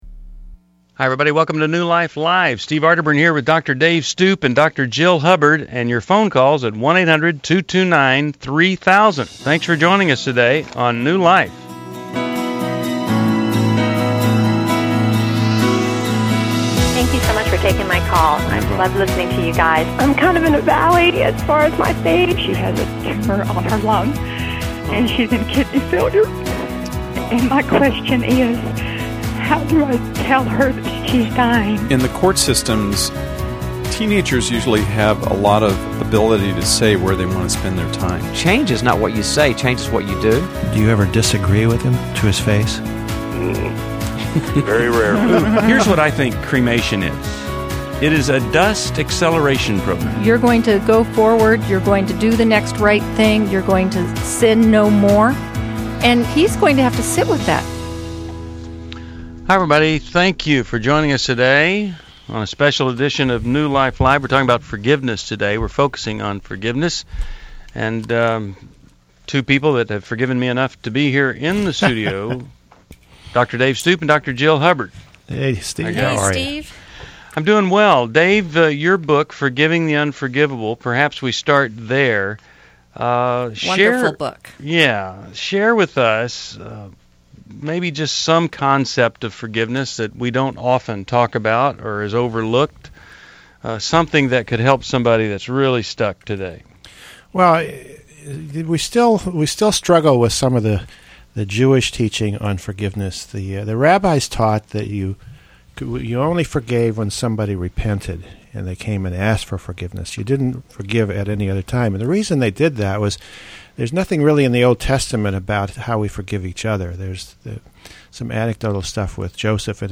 Explore forgiveness, dating, and sexual integrity in New Life Live: October 14, 2011. Callers tackle heavy topics like self-forgiveness and healing from betrayal.